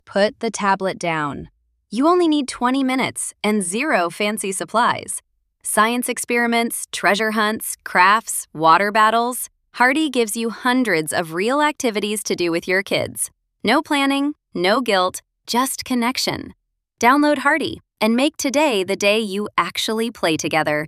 voiceover.mp3